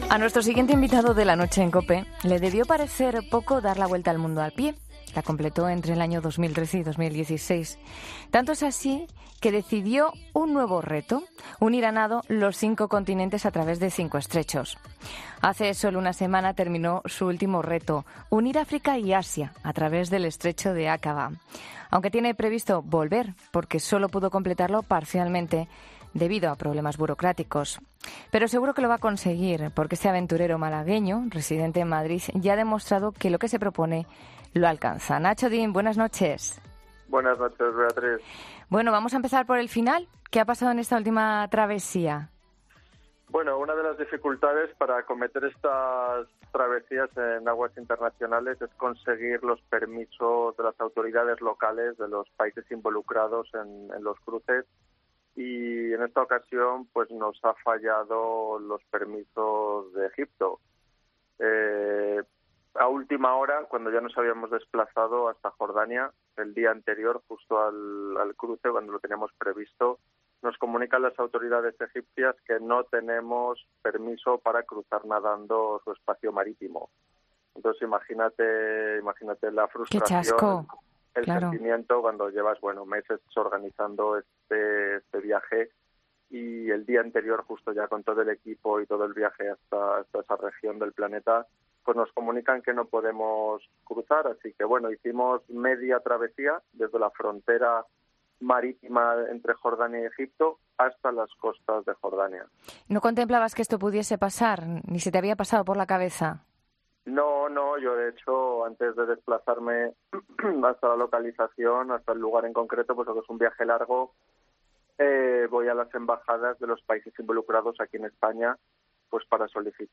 En 'La Noche de COPE' hablamos con este aventurero que quiere unir a nado los cinco continentes a través de cinco estrechos.